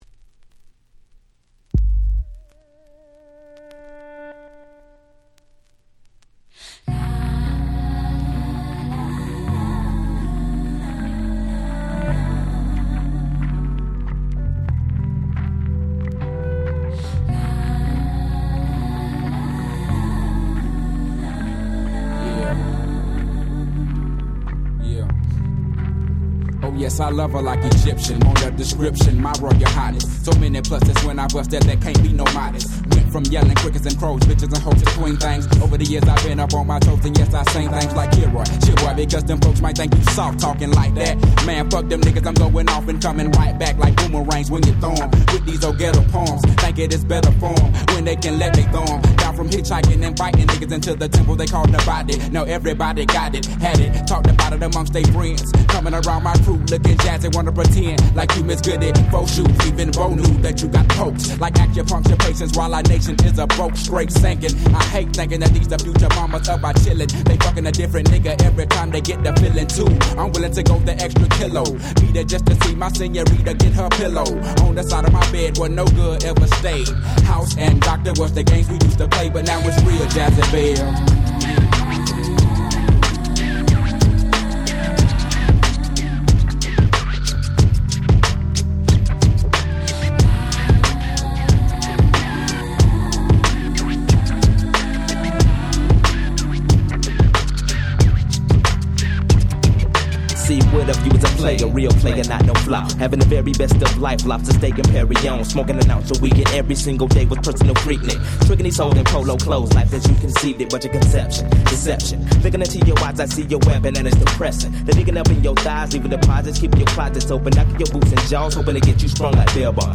97' Smash Hit Hip Hop !!
この独特な雰囲気、本当にクセになります！！